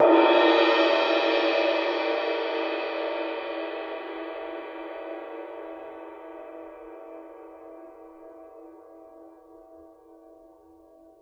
susCymb1-hit_mp_rr1.wav